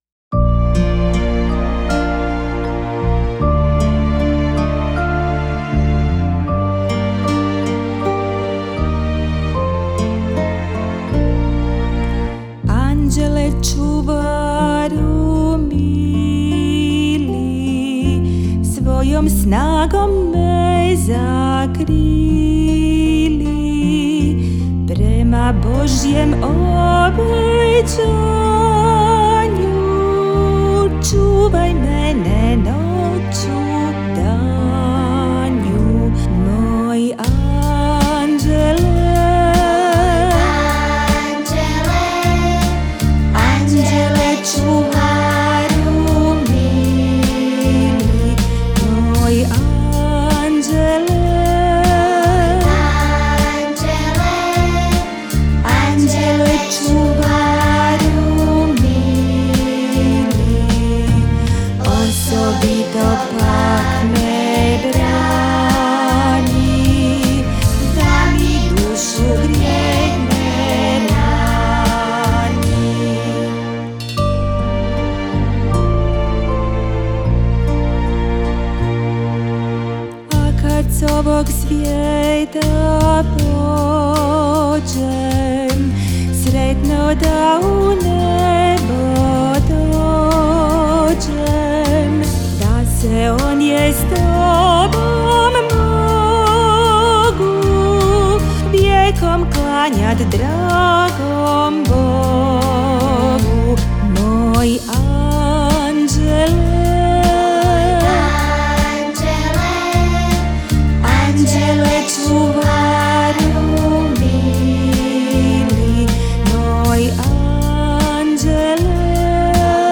Liturgijske
Dječja